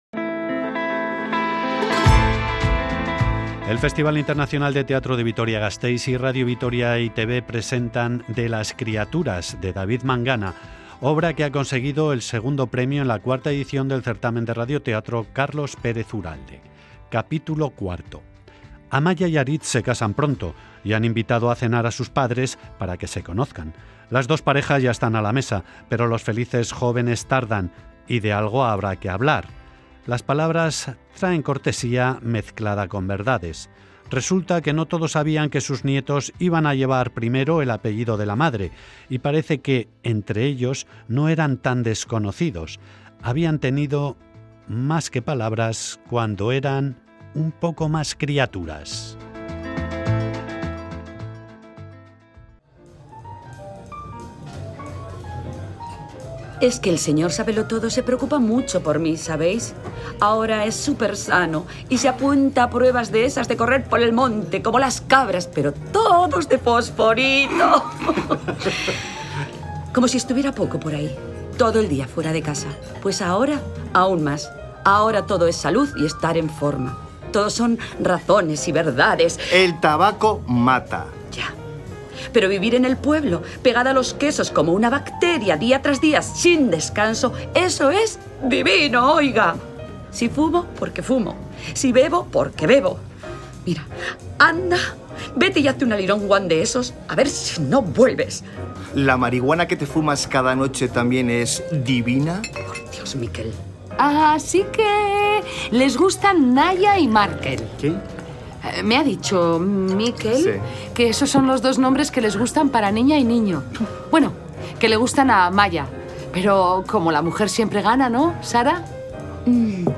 Radio Vitoria RADIO-TEATRO-URALDE ‘De las critaturas’ – Cap. 4 Publicado
Grabado en Sonora Estudios.